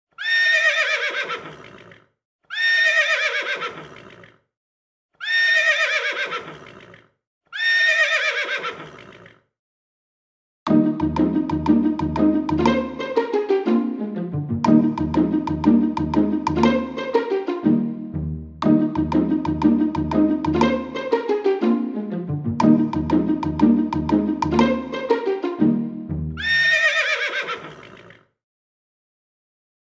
קבצי שמע לתרגול (שירי נגינה עליזים ומלאי מוטיבציה) –
Track-14-Horse.mp3